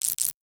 NOTIFICATION_Subtle_11_mono.wav